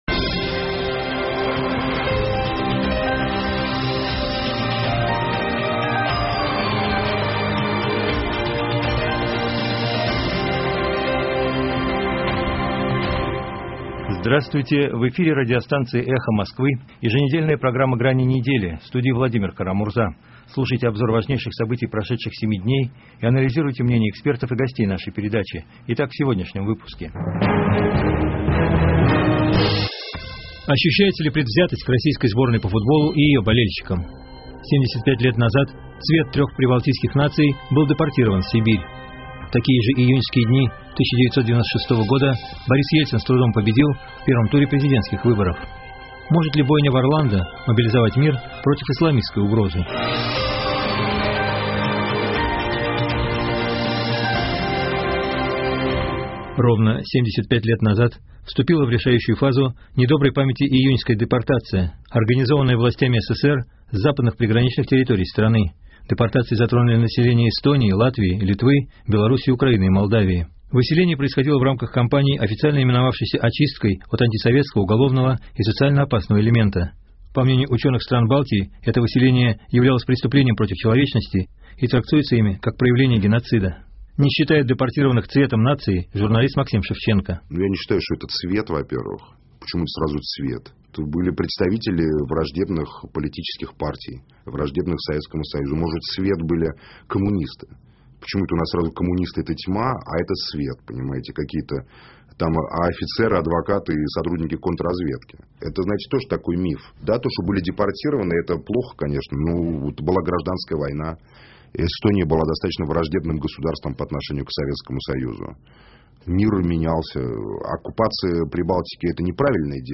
Здравствуйте, в эфире радиостанции «Эхо Москвы» еженедельная программа «Грани недели», в студии Владимир Кара-Мурза. Слушайте обзор важнейших событий прошедших 7 дней и анализируйте мнения экспертов и гостей нашей передачи.